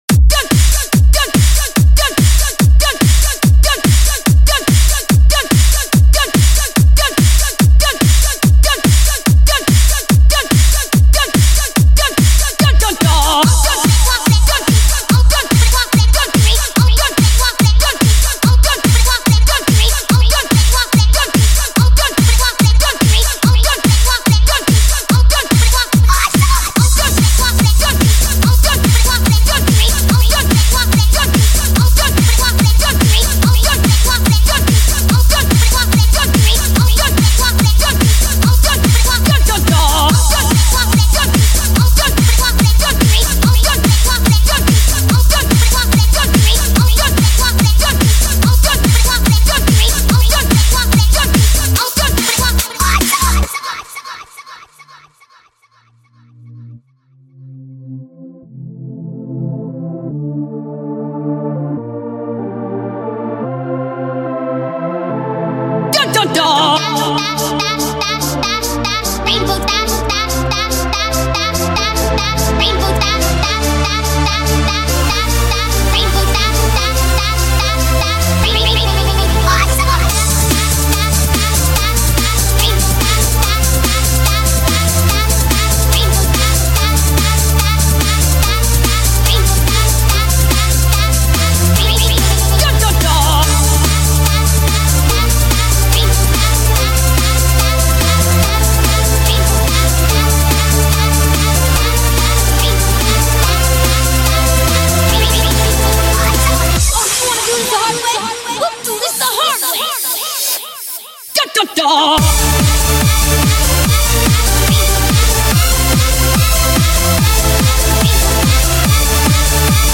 My first attempt to do Trance with an Epic Wibe to it.
IS IT LOUD ENOUGH FOR YOU?